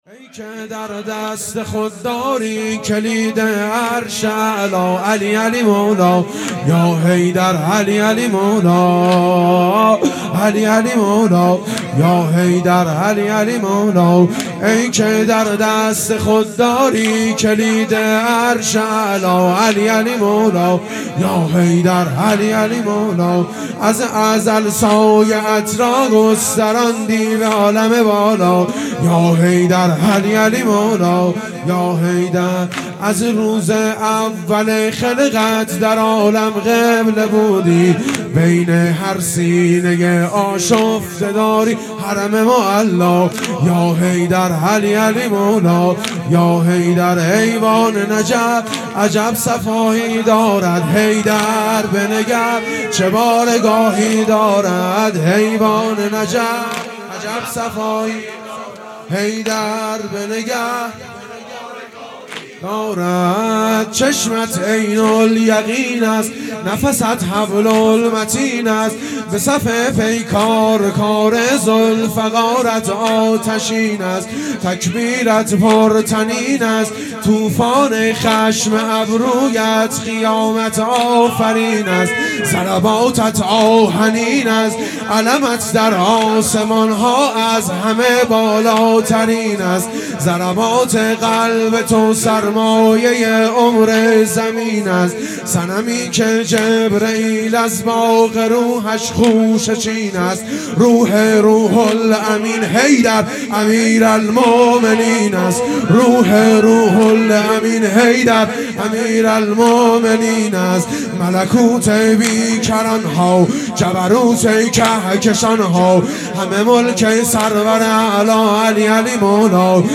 واحد | ایوان نجف عجب صفایی دارد
شام‌شهادت‌حضرت‌زهرا(س)